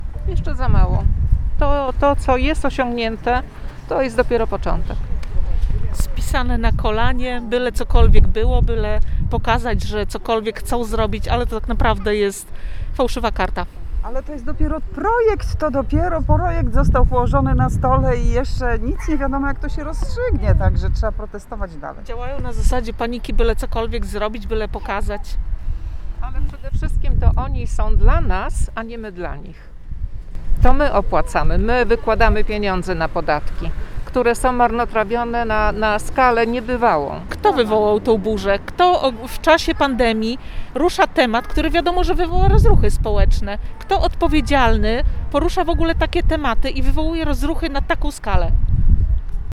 Co na to uczestniczki protestu?